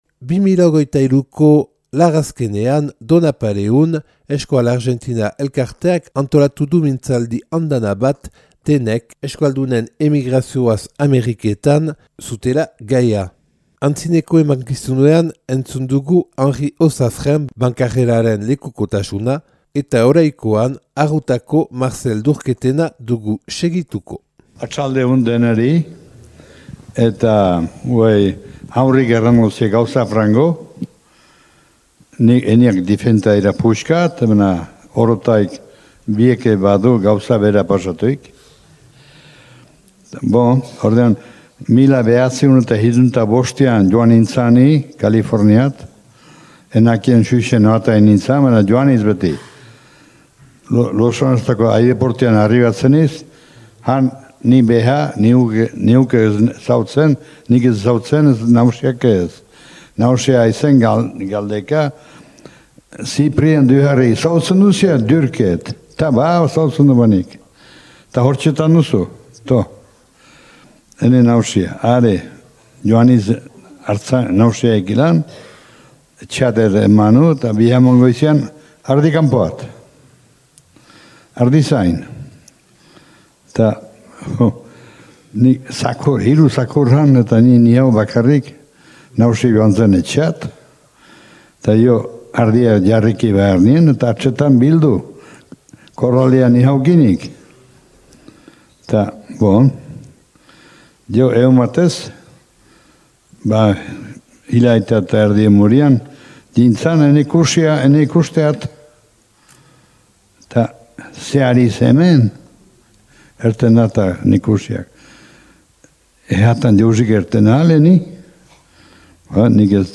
(Grabaketa 2023. Urriaren 25an Donapaleun Bideak kulturgunean, Euskal Argentina elkarteak antolaturik).